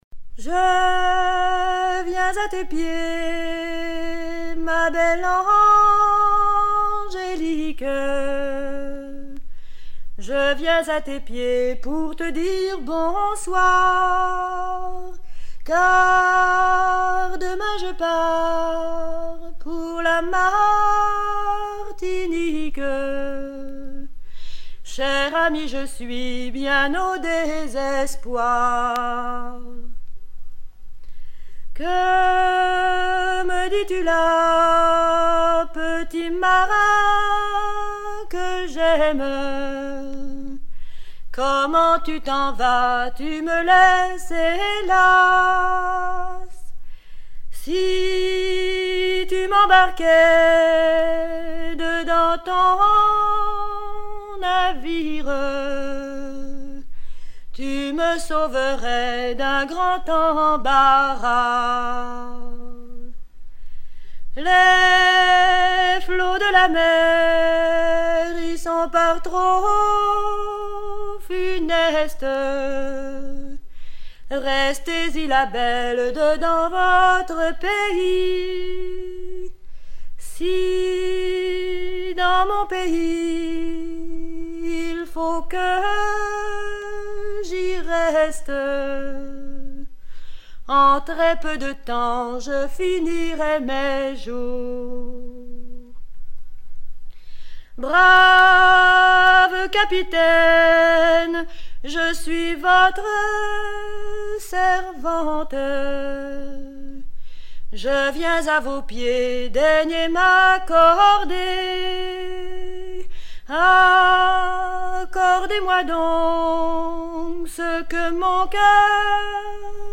Version recueillie en 1969
Genre strophique